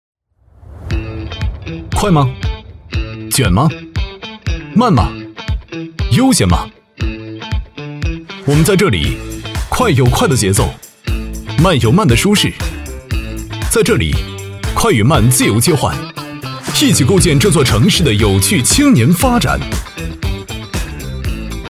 国语配音
男558-广告-时尚公益广告-这座城.mp3